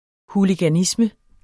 Udtale [ huligaˈnismə ]